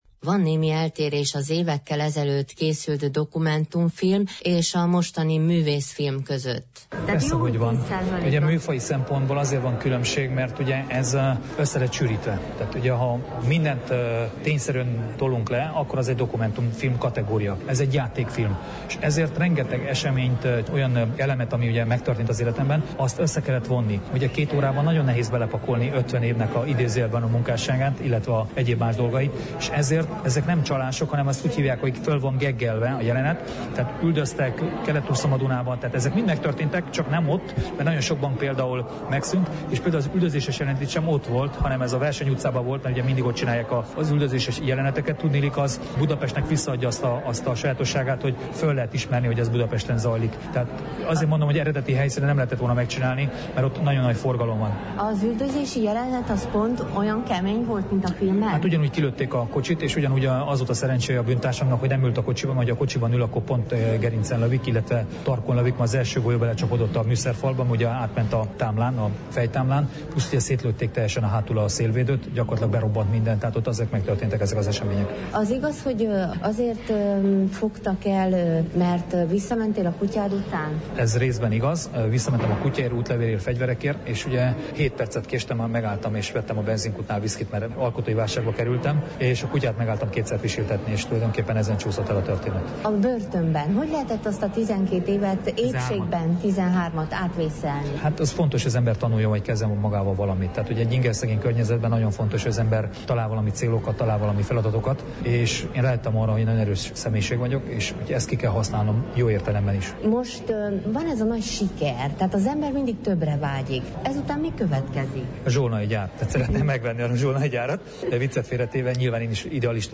A Kultúrpalotában vetítették A Viszkist - Ambrus Attilával beszélgettünk - Marosvasarhelyi Radio